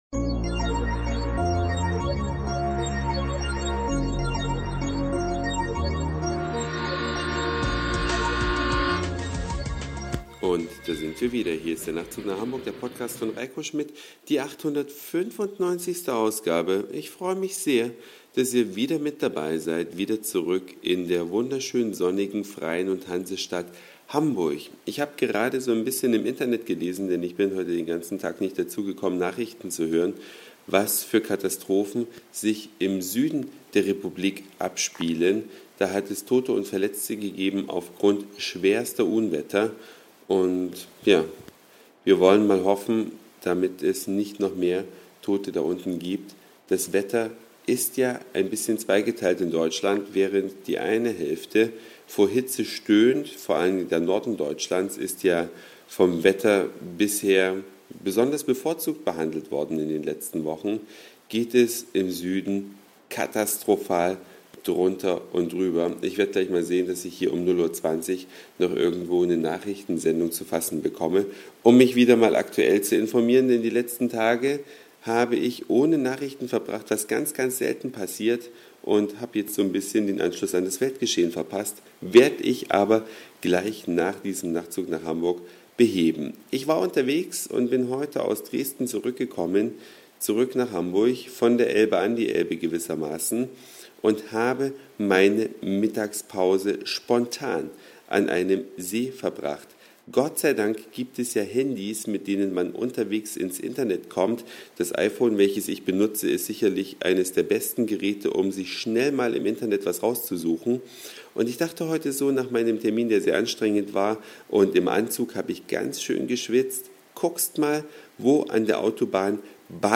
Eine Reise durch die Vielfalt aus Satire, Informationen, Soundseeing und Audioblog.
Spontan mit dem iPhone einen Baggersee gefunden.